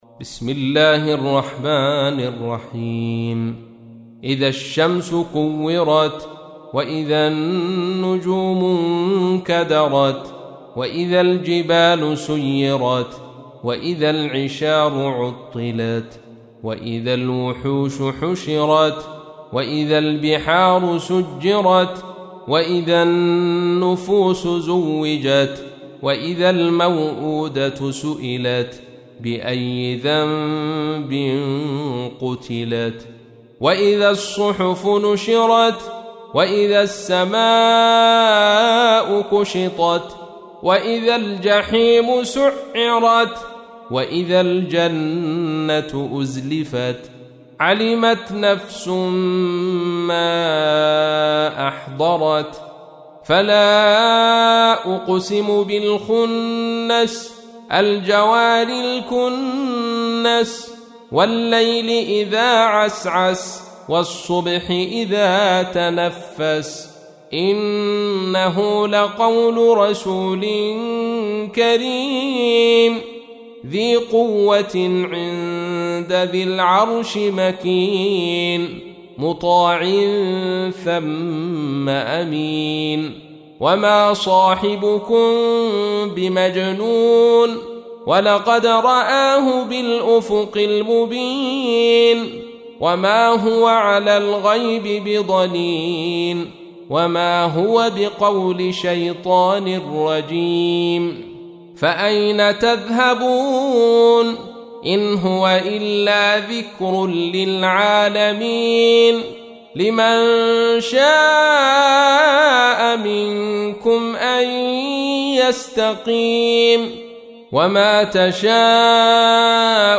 سورة التكوير | القارئ عبدالرشيد صوفي